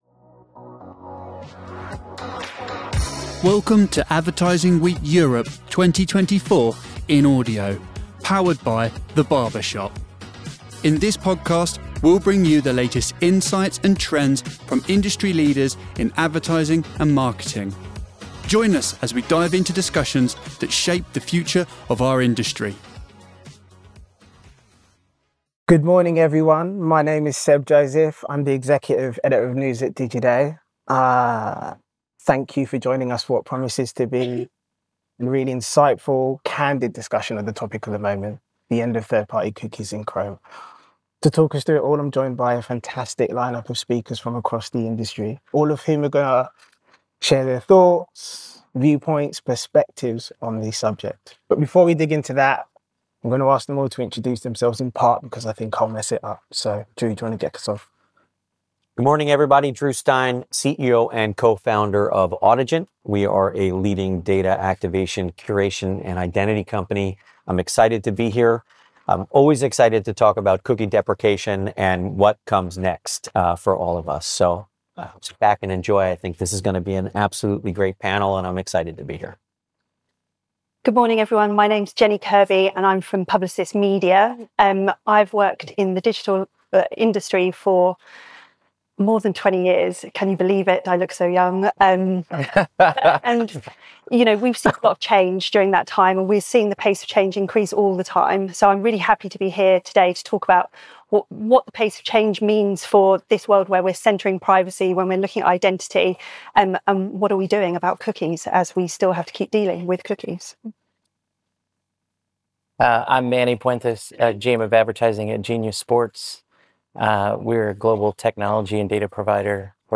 This session explores innovative solutions to maintain personalisation and effectiveness in a privacy-first world, offering practical advice for navigating the changing landscape of digital advertising.